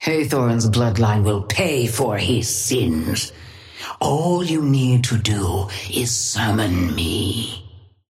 Sapphire Flame voice line - Hathorne's bloodline will pay for his sins. All you need to do is summon me.
Patron_female_ally_hornet_start_02.mp3